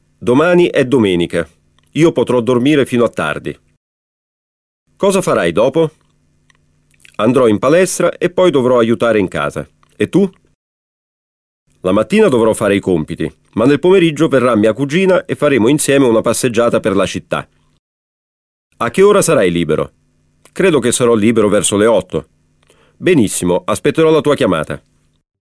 Dialogo – Диалог